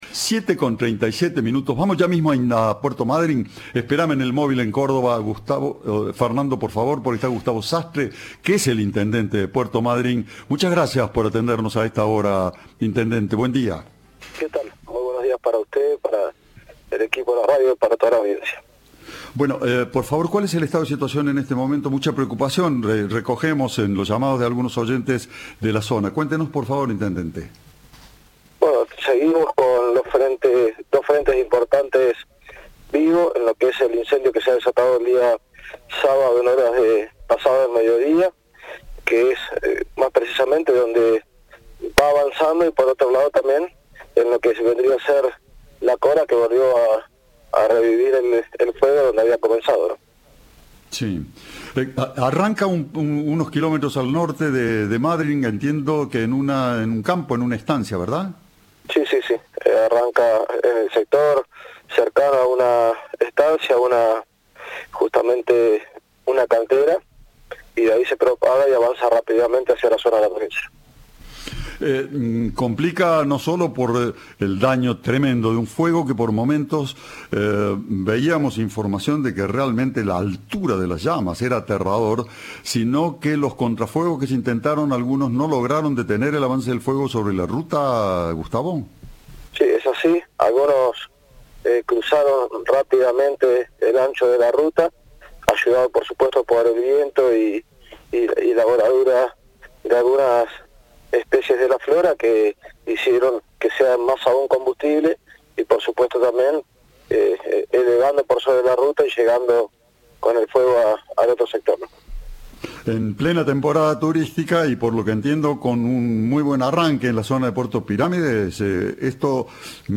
El intendente Gustavo Sastre dijo a Cadena 3 que "hay dos frentes importantes".